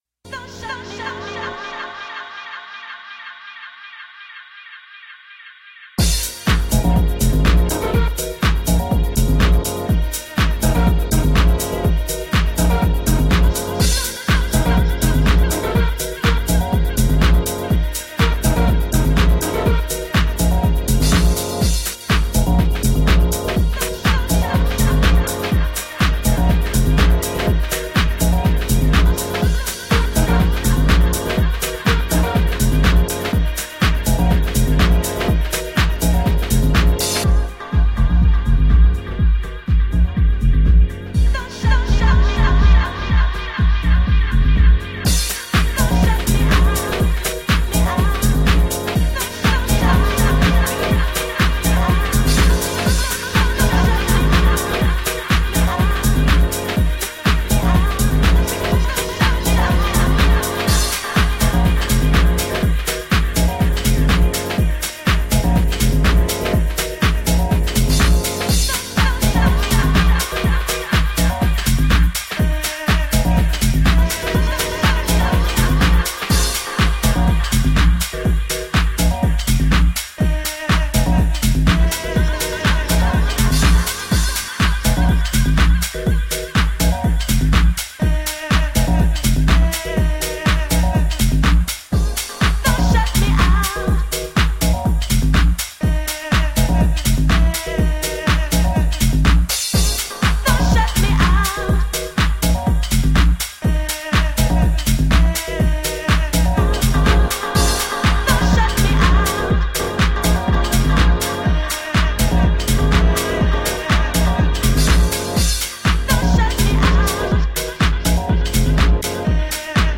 US-style deep house tracks